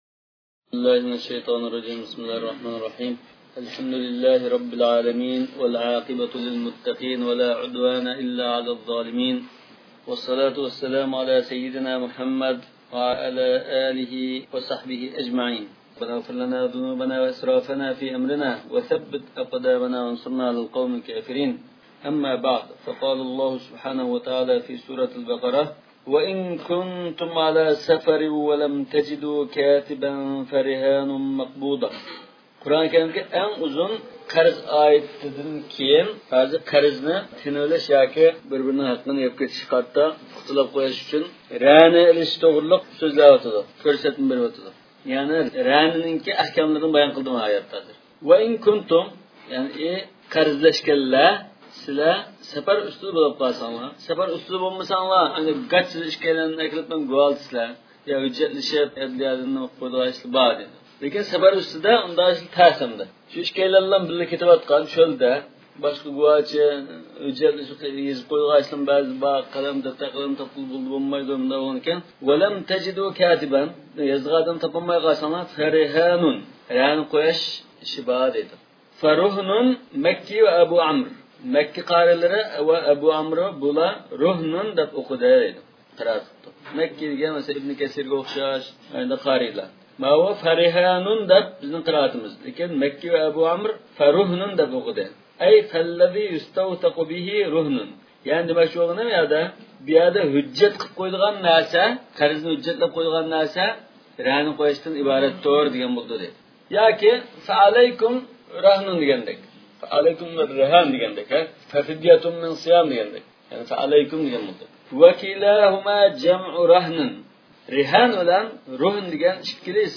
ئاۋازلىق دەرسلەر